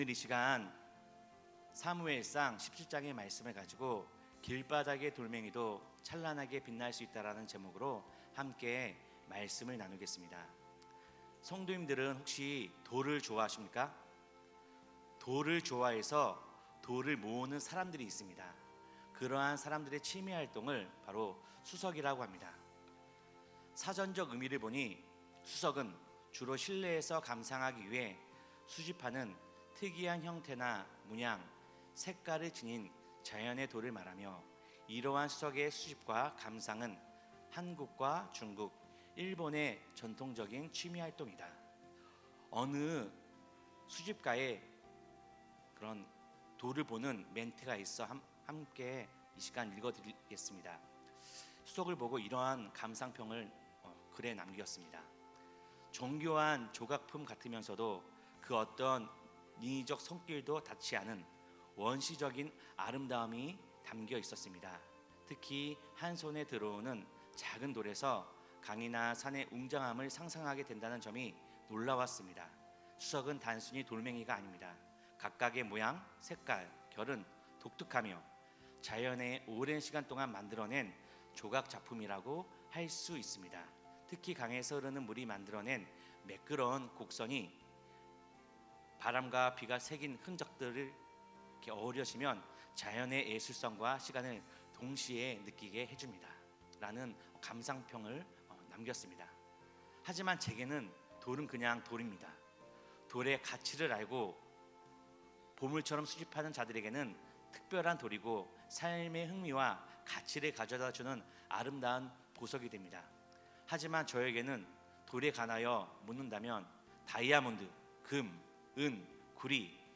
목록 share 주일설교 의 다른 글